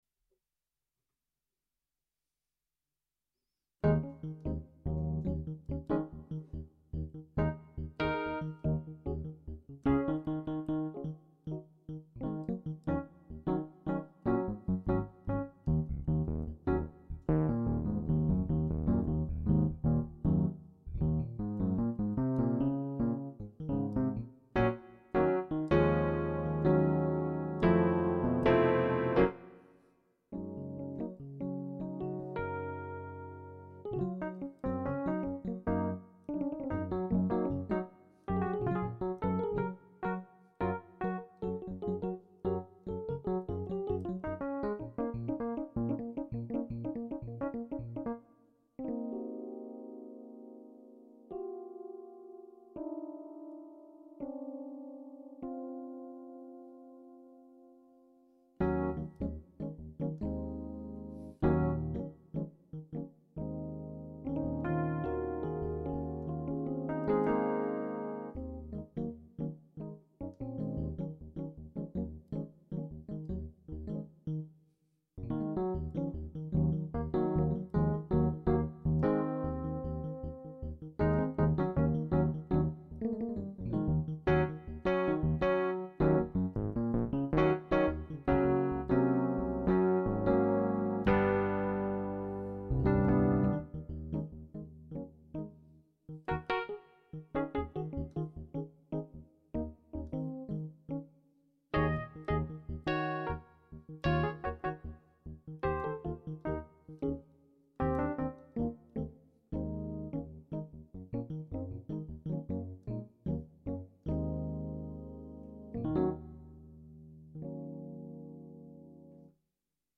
Improvisations réalisées entre juillet et novembre 2014.